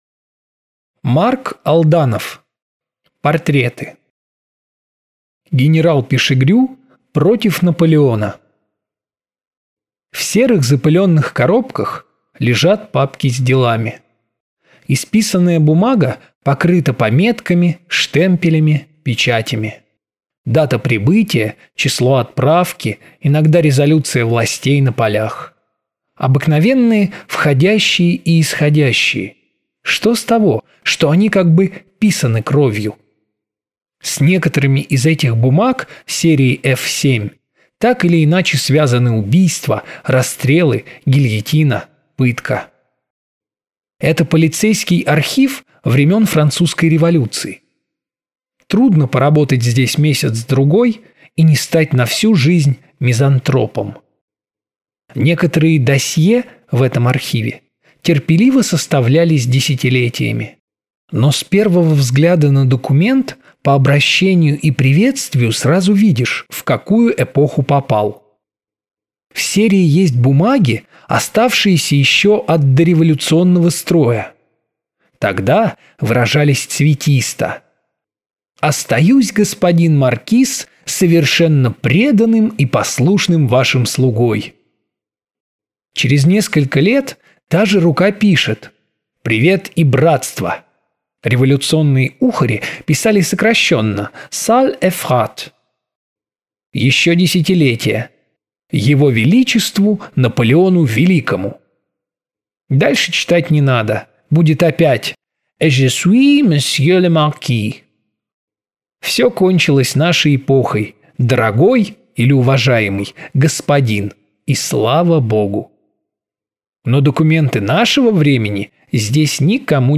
Аудиокнига Генерал Пишегрю против Наполеона. Полковник Пикар и дело Дрейфуса. Сент-эмилионская трагедия. Сперанский и декабристы | Библиотека аудиокниг